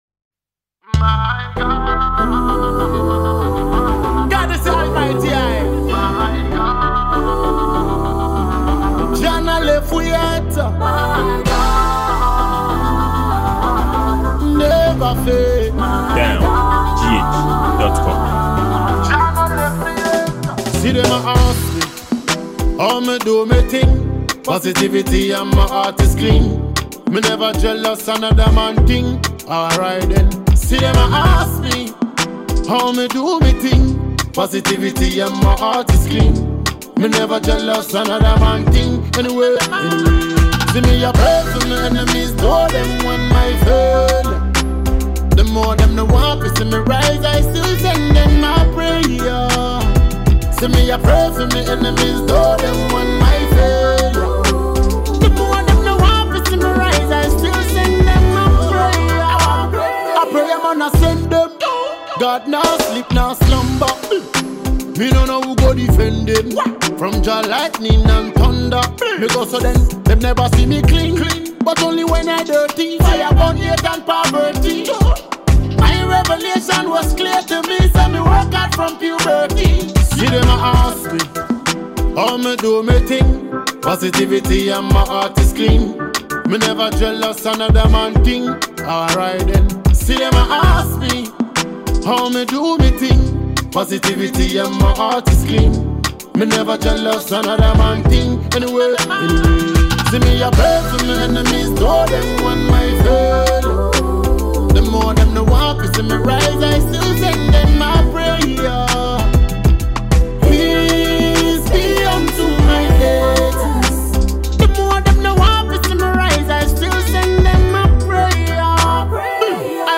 a Ghanaian reggae and afrobeat dancehall musician.